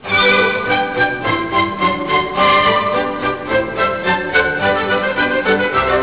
基督教聖歌練習
直接點選歌名可聽到該歌曲合唱旋律
點選歌名下方各聲部可聽到各聲部個別旋律( 均有鋼琴作伴奏, 該聲部聲音以各種不同樂器聲音表達, 以便有別於鋼琴伴奏音 )